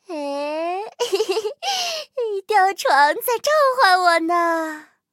M10狼獾中破修理语音.OGG